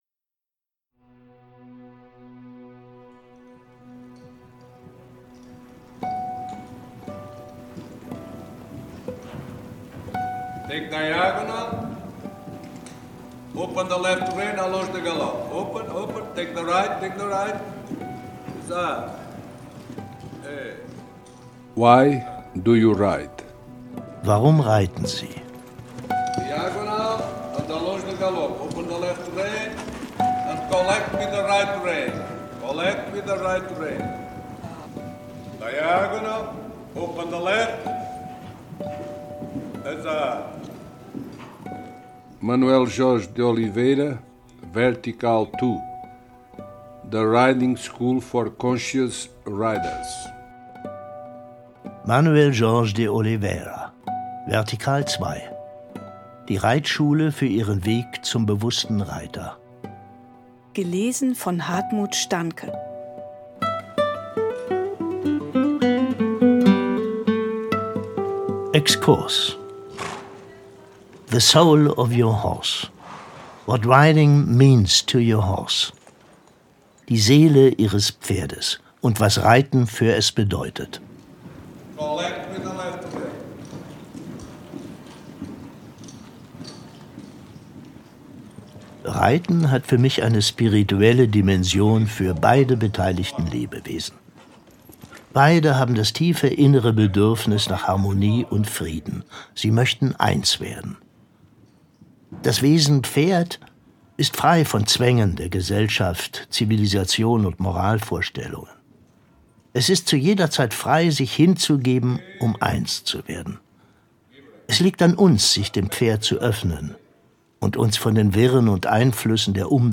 Das Hörbuch